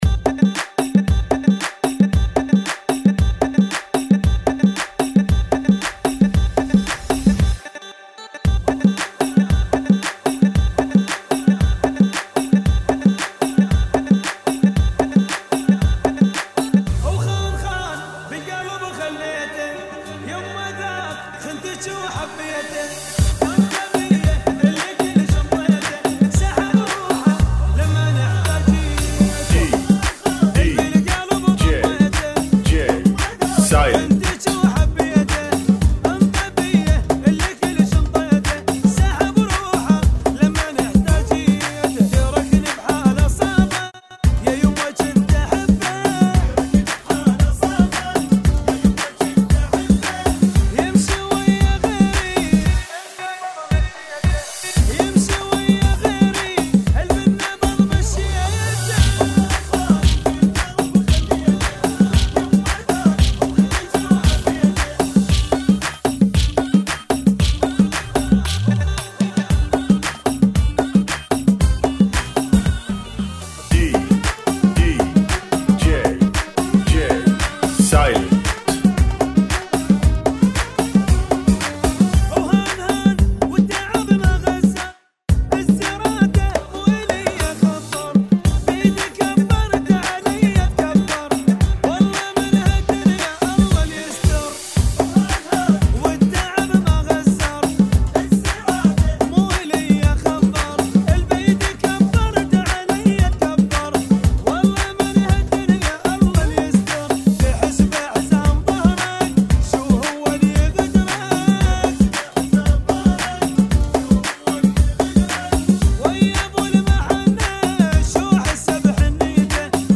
114 BPM